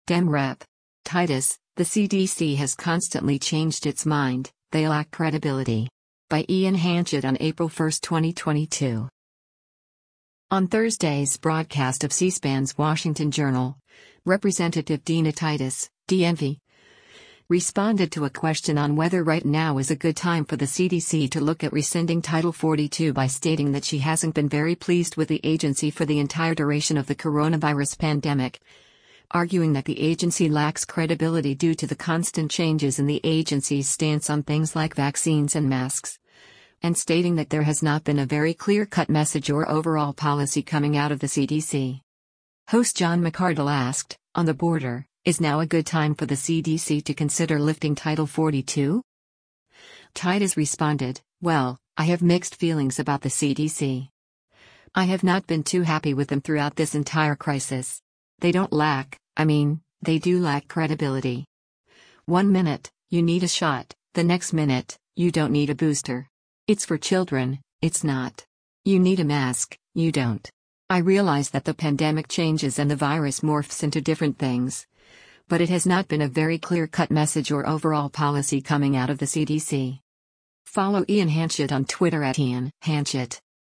On Thursday’s broadcast of C-SPAN’s “Washington Journal,” Rep. Dina Titus (D-NV) responded to a question on whether right now is a good time for the CDC to look at rescinding Title 42 by stating that she hasn’t been very pleased with the agency for the entire duration of the coronavirus pandemic, arguing that the agency lacks credibility due to the constant changes in the agency’s stance on things like vaccines and masks, and stating that there “has not been a very clear-cut message or overall policy coming out of the CDC.”